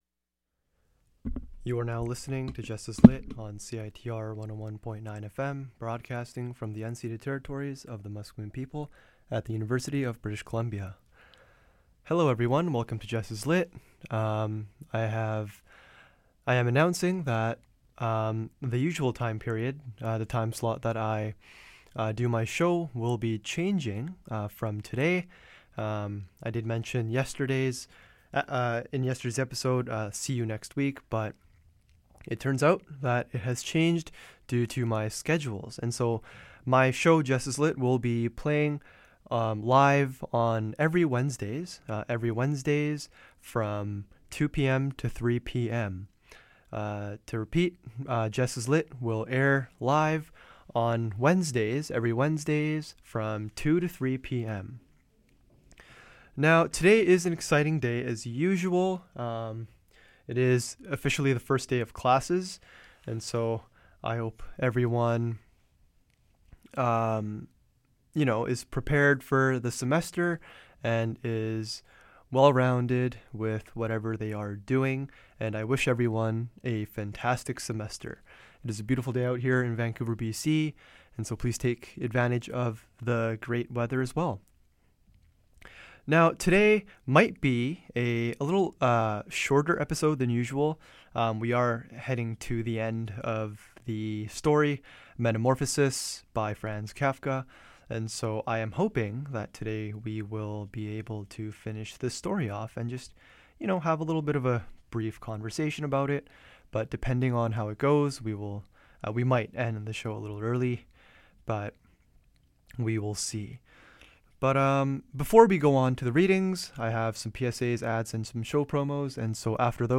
This episode is the finale of the readings series: The Metamorphosis by Franz Kafka. I briefly analysis the main concepts and meanings of the story at the end.